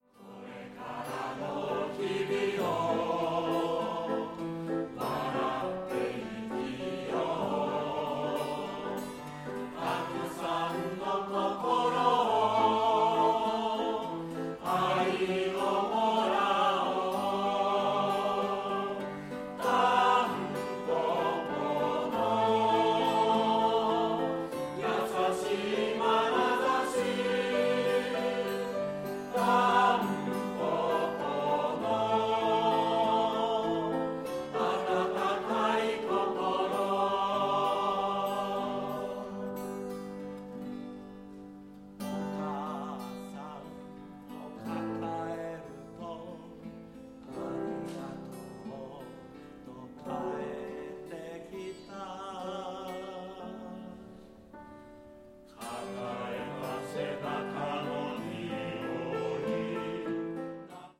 オリジナルコンサート　総評